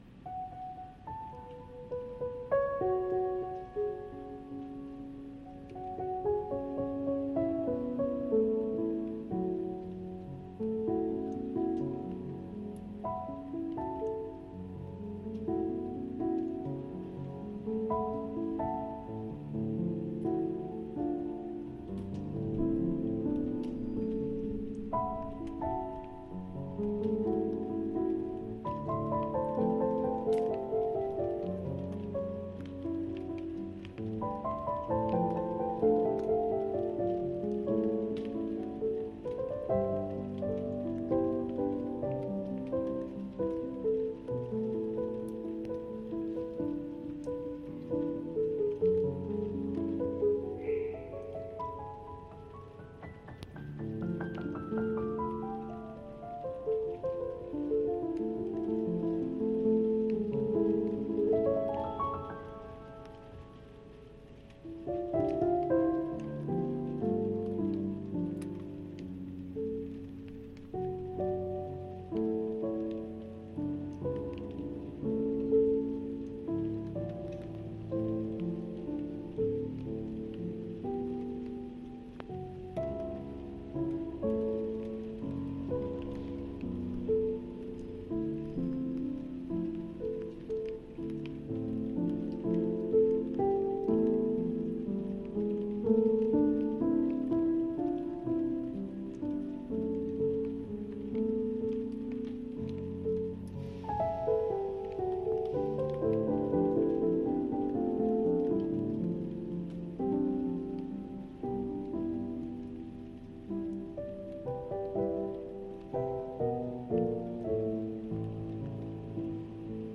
Playing this weird but cool Tape piano from , in the rain as captured from very wet dynamic mics in my back garden.